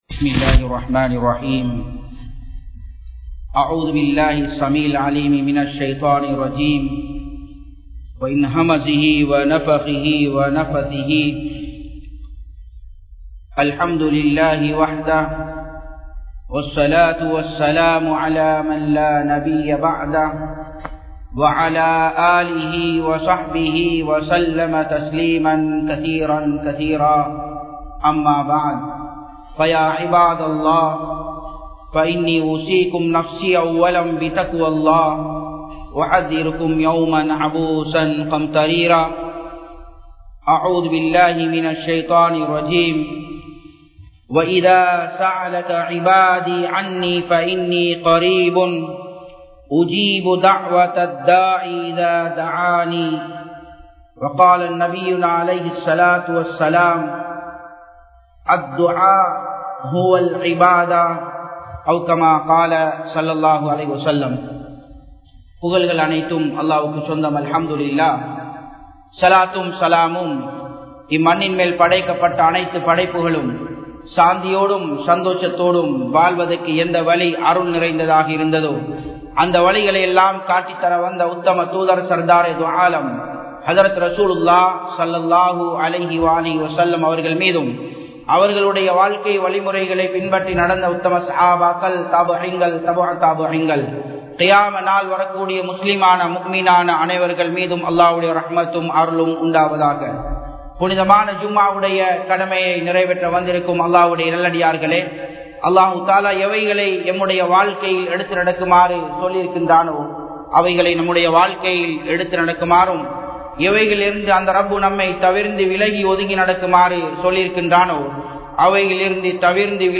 Kadan Niraiveara 06 Duakkal (கடன் நிறைவேற 06 துஆக்கள்) | Audio Bayans | All Ceylon Muslim Youth Community | Addalaichenai
Japan, Nagoya Port Jumua Masjidh 2017-10-13 Tamil Download